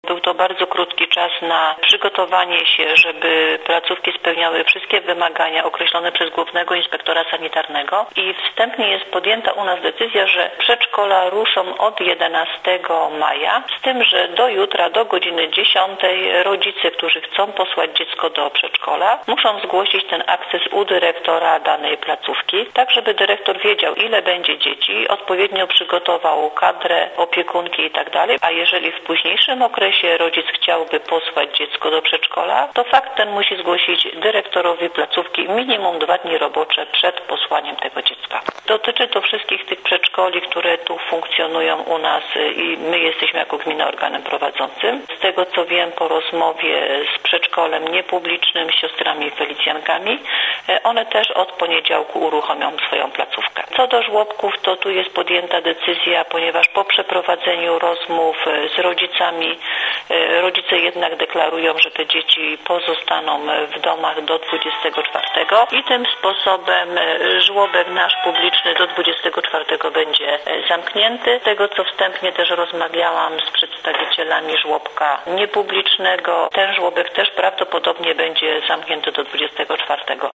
– mówiła wiceburmistrz Praszki, Danuta Janikowska.